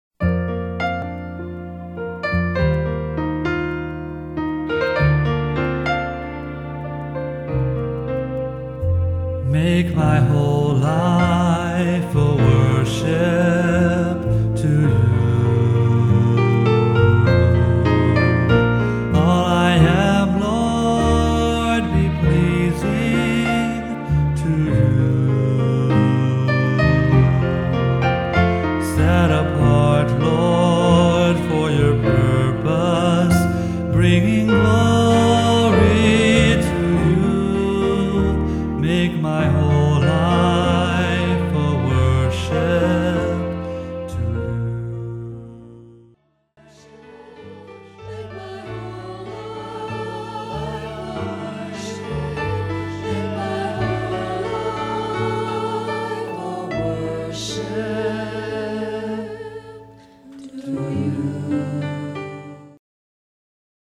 A worship album encouraging you to listen or sing along.